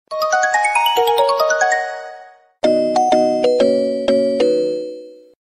• Качество: 129, Stereo
Iphone